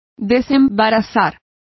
Complete with pronunciation of the translation of rids.